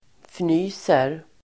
Uttal: [fn'y:ser]
fnyser.mp3